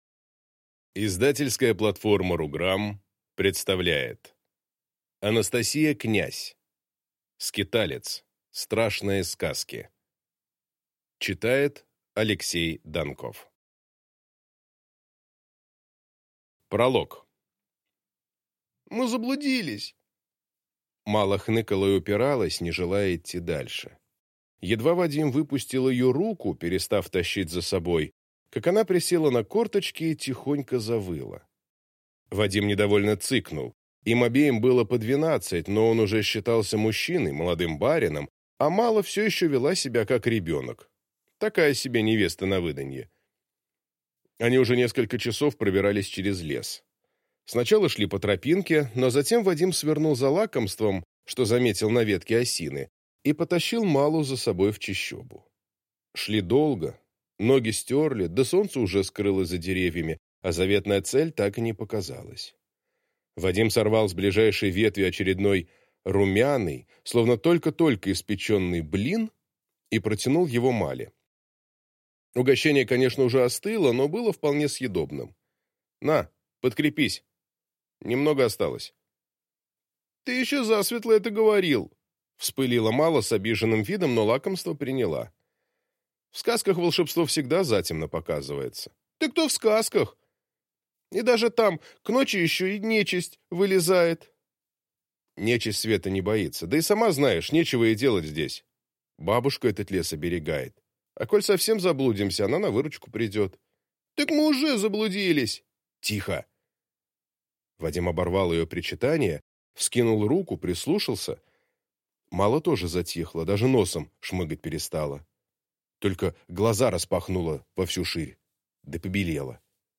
Аудиокнига Скиталец. Страшные сказки | Библиотека аудиокниг